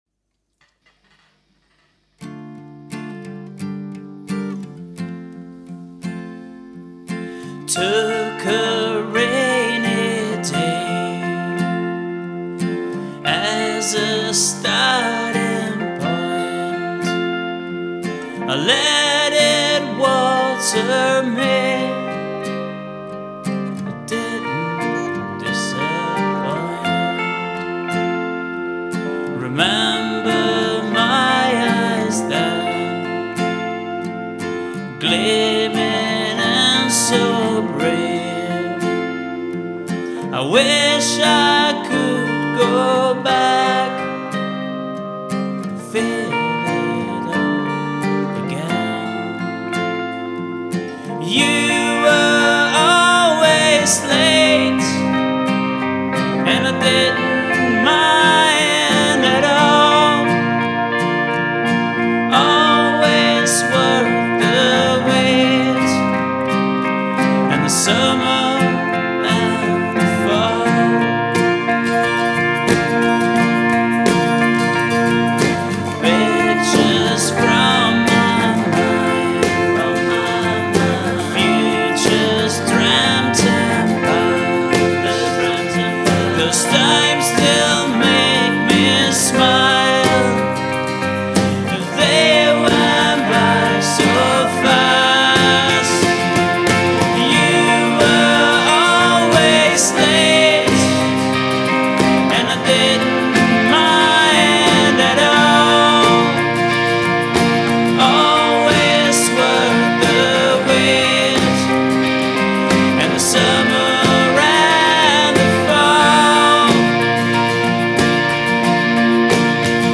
vocals, guitars, bass, drums, percussion